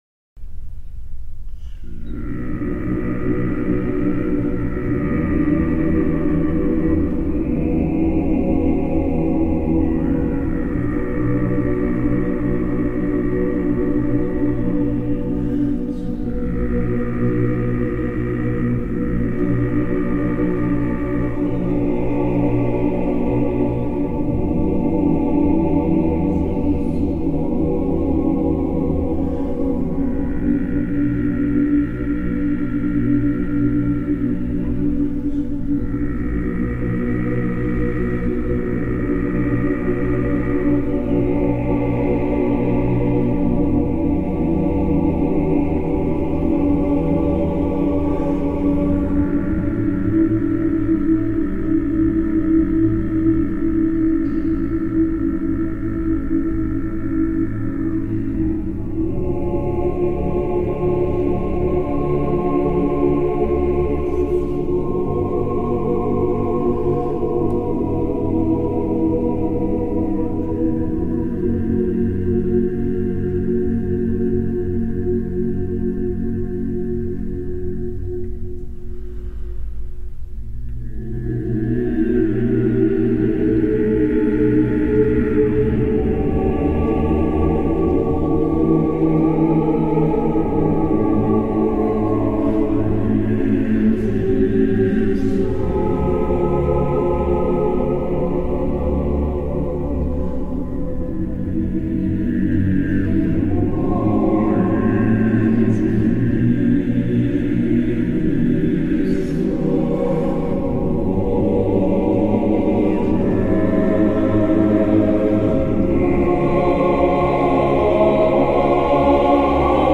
One of my favorite chants, no amounts of cthonic irony-layered anti-christ bs can outmatch this